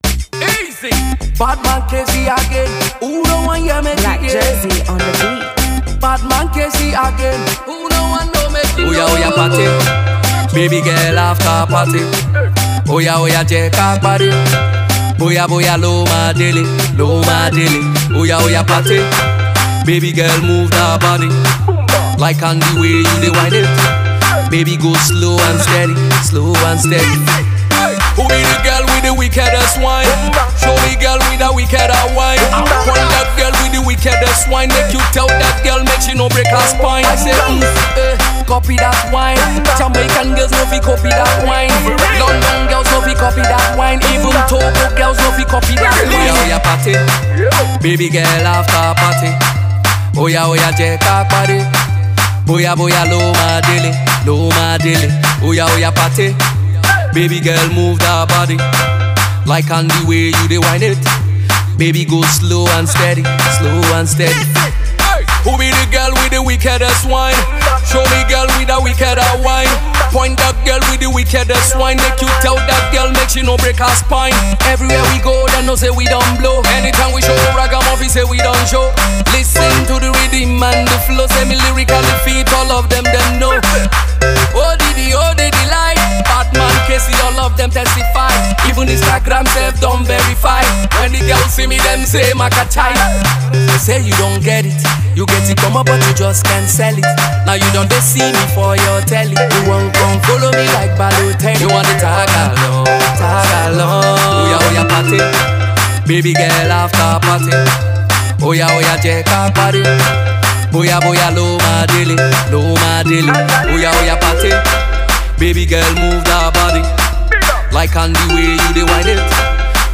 Afro Dance Hall single